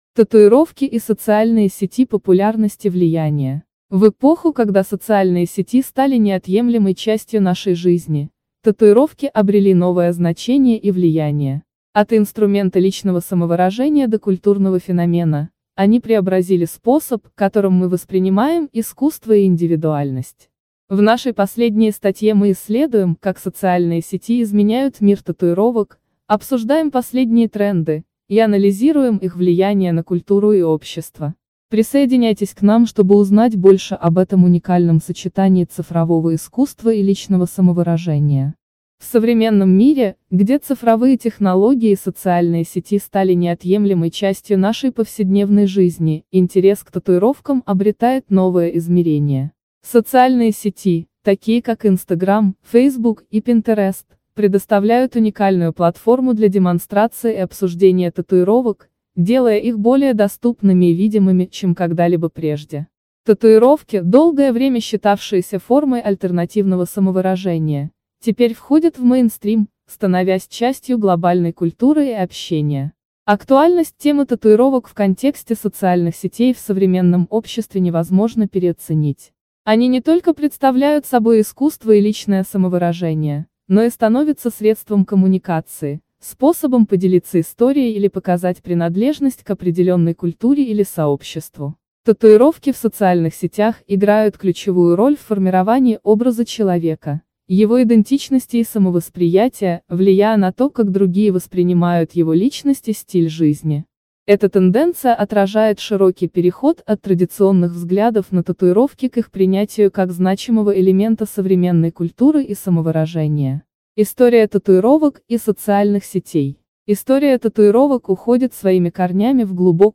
Татуировки-и-социальные-сети-популярность-и-влияние-аудио-версия-статьи-для-tatufoto.com_.mp3